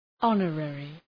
Προφορά
{‘ɒnə,rerı}